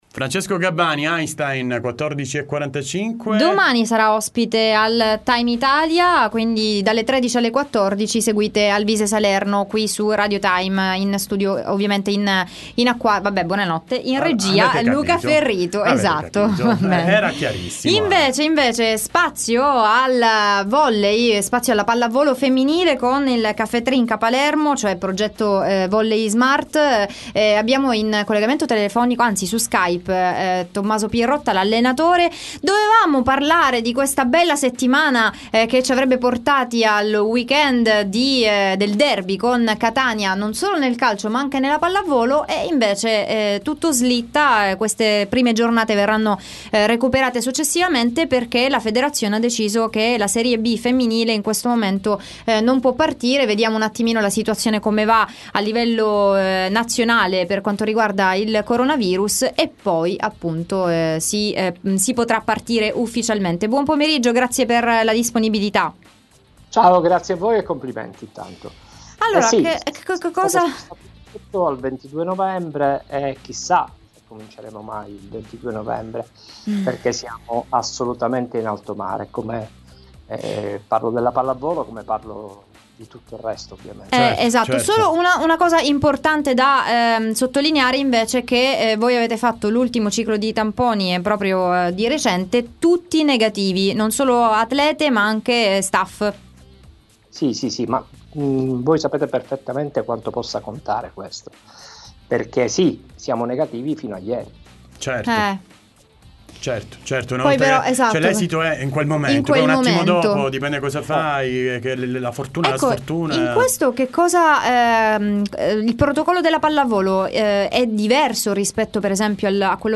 Time Sport Intervista